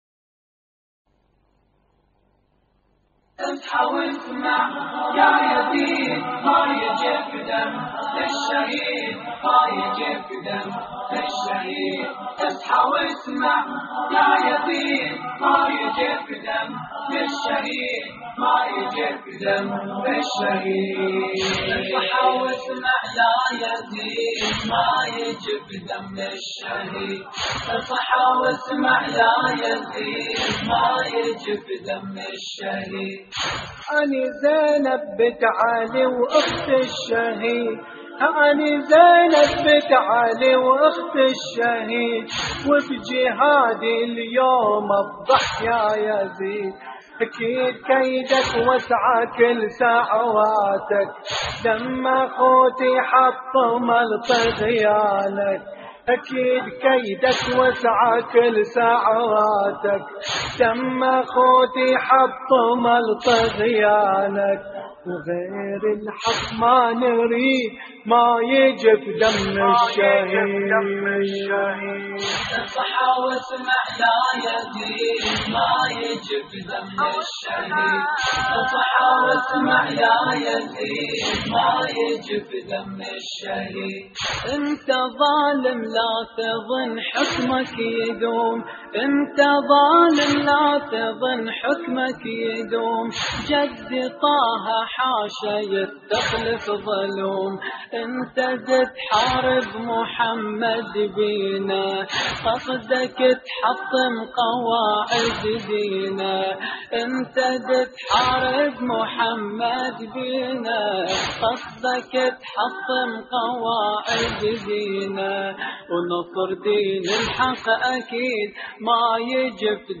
استديو «الظليمة»